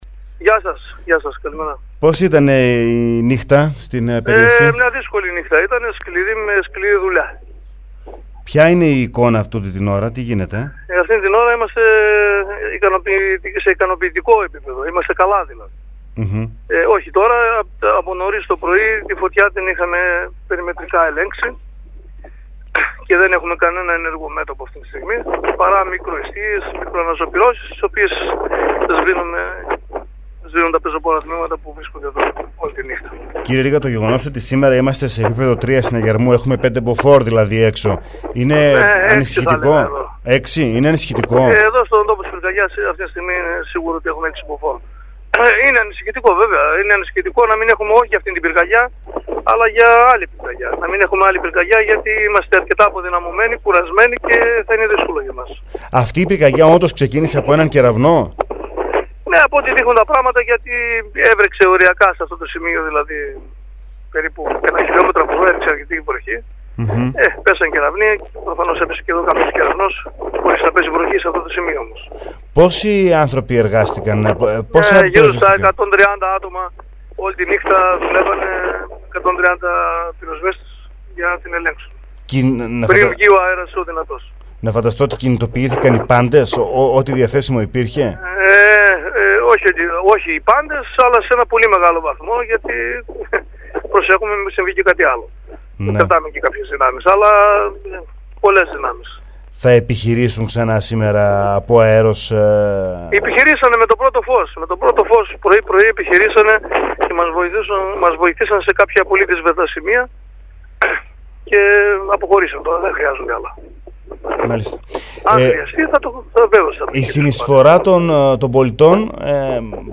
στο ραδιόφωνο Sferikos 99,3